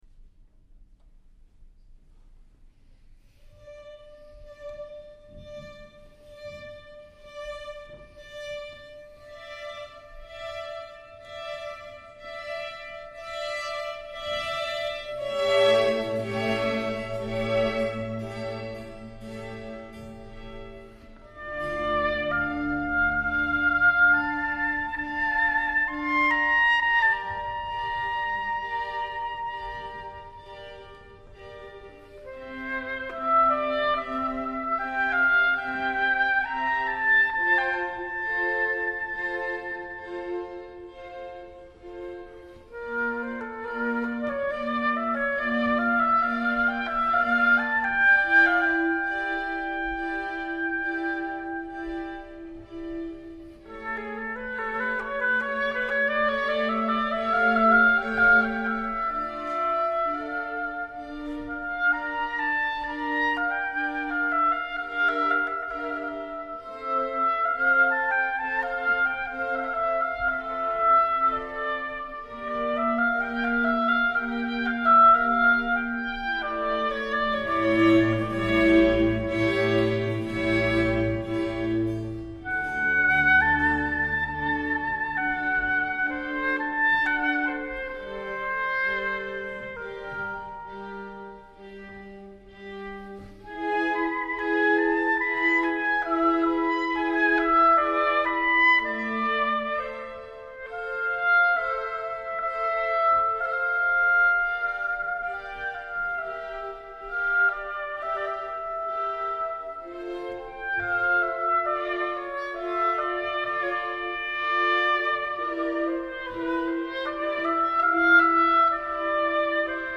The Church of San Bartolomeo in Brugherio (MB)